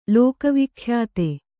♪ lōka vikhyāte